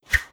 Close Combat Swing Sound 53.wav